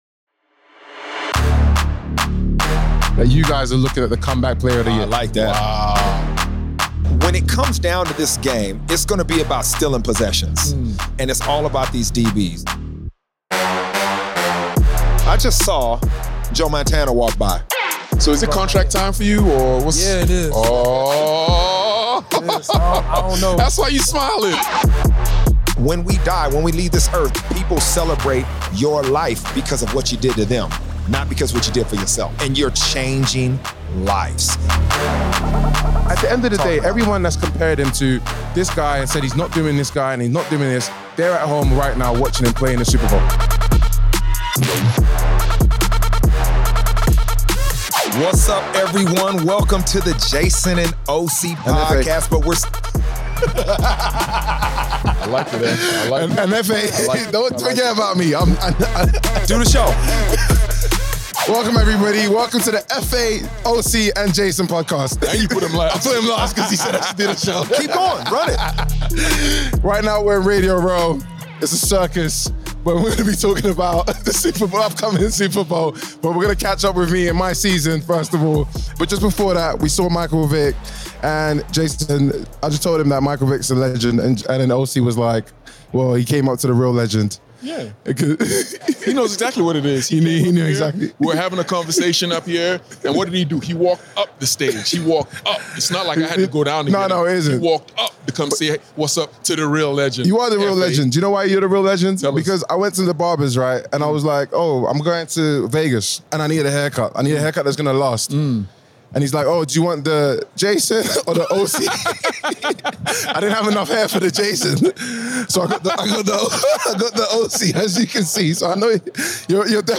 SUPER BOWL LVIII PREVIEW LIVE FROM RADIO ROW
The biggest game in American sports is upon us so Jason & Osi are in Las Vegas to preview it from Radio Row alongside the world's media and the great and the good of the game.